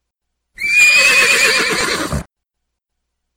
Nada notifikasi WA suara Kuda asli
Genre: Nada dering binatang
Detail: Suara kuda yang khas—nyaring dan unik—bisa jadi pilihan yang gokil banget buat gantiin nada notif standar yang itu-itu aja. Bayangin deh, tiap kali ada pesan masuk, HP kamu nge-neigh kayak kuda.
nada-notifikasi-wa-suara-kuda-asli.mp3